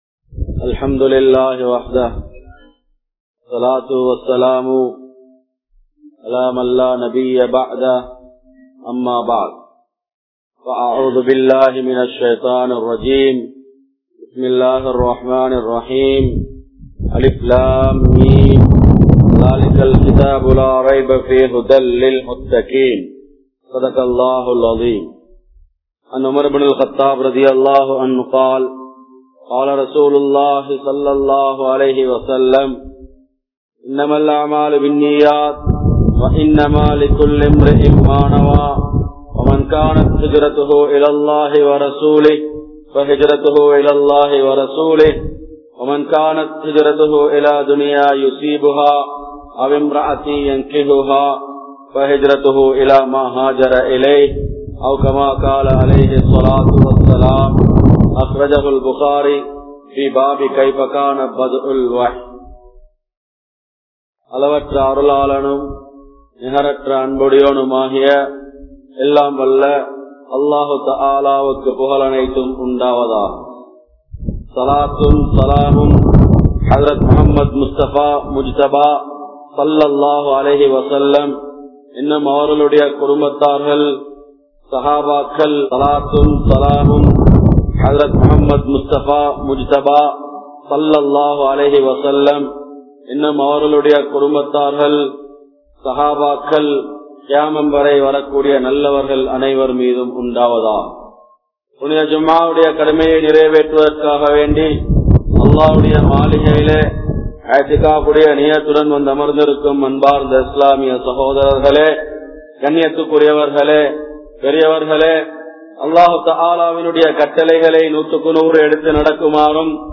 Aaroakkiyamaana Vaalkai (ஆரோக்கியமான வாழ்க்கை) | Audio Bayans | All Ceylon Muslim Youth Community | Addalaichenai
Kanampittya Masjithun Noor Jumua Masjith